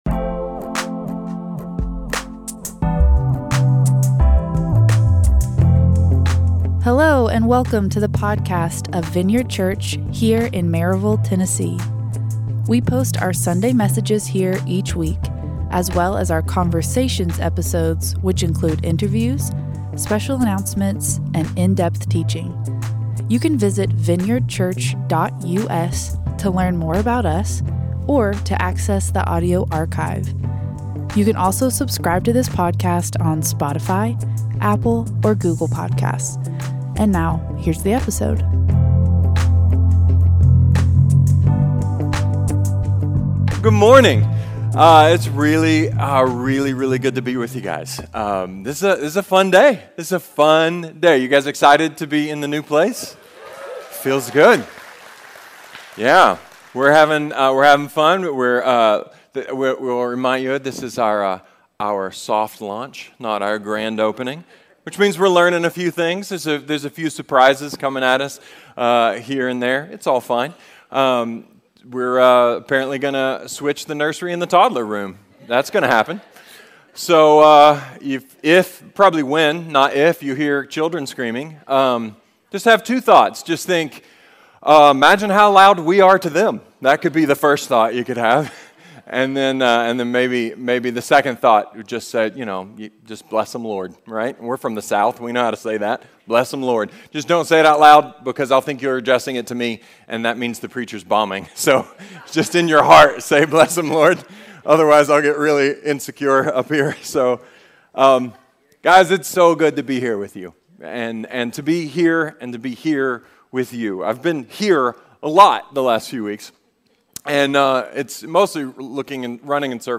A sermon about the hotspot of our fundamental longings, our restless hearts, and a standing invitation to breathe.